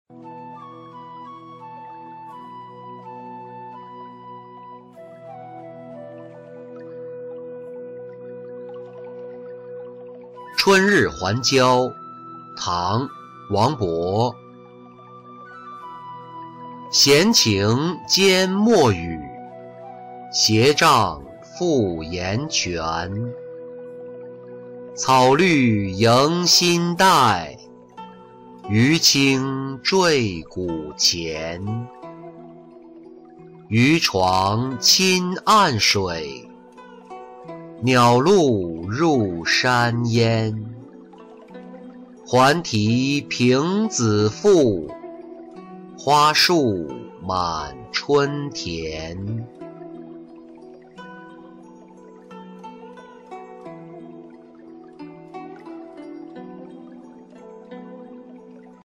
春日还郊-音频朗读